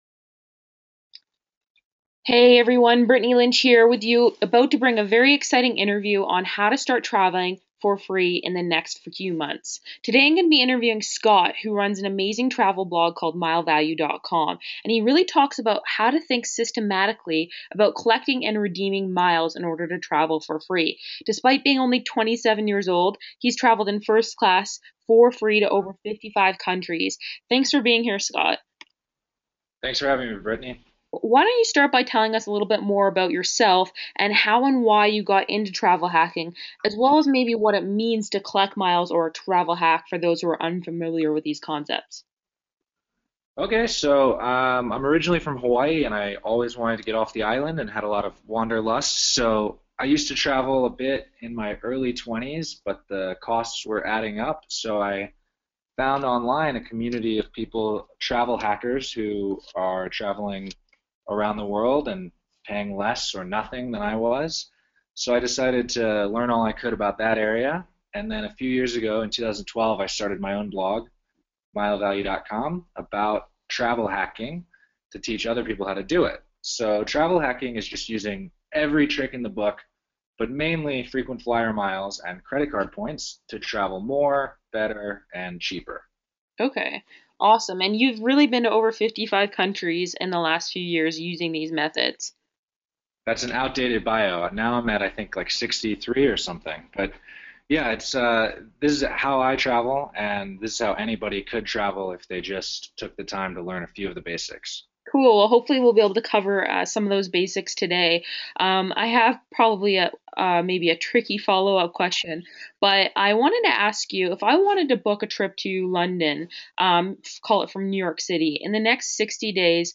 Edit Element Clone Element Advanced Element Options Move Remove Element Audio File For Interview Download to listen on your iTunes or audio player.